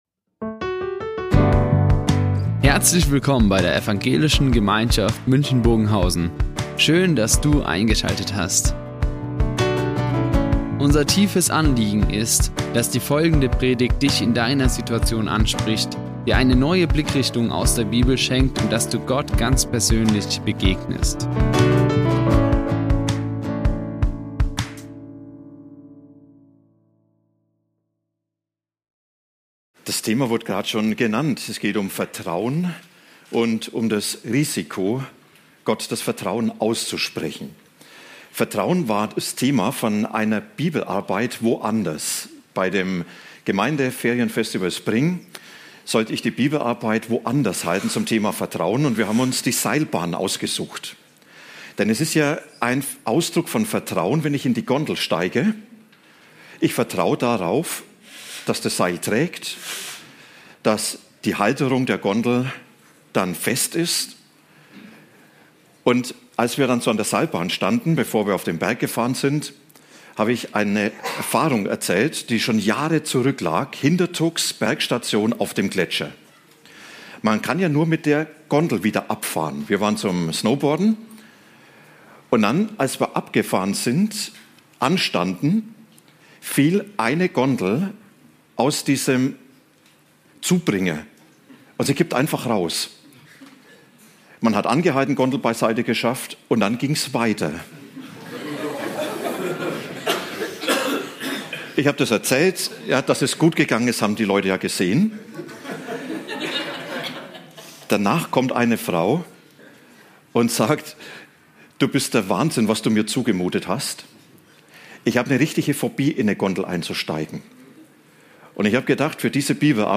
Die Aufzeichnung erfolgte im Rahmen eines Livestreams.